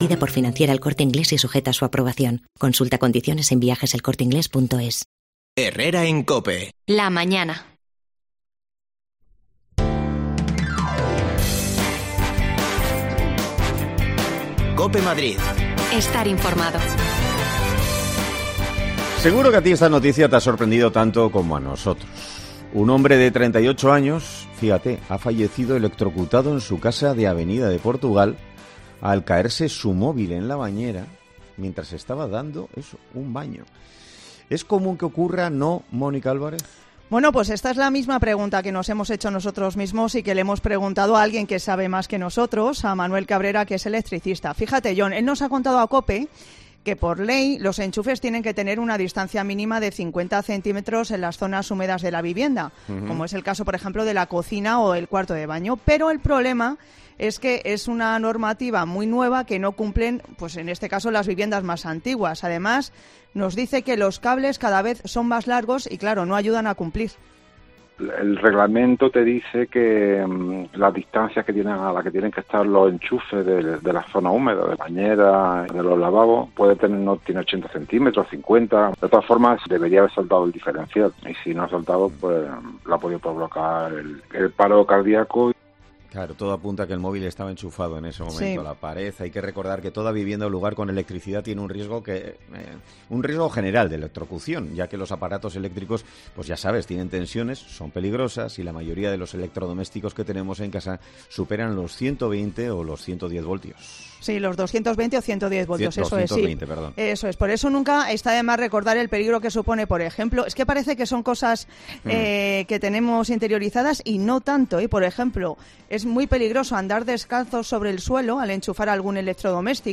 AUDIO: Escucha los consejos de un electricista para no llevarnos un susto con los aparatos eléctrricos en casa
Las desconexiones locales de Madrid son espacios de 10 minutos de duración que se emiten en COPE , de lunes a viernes.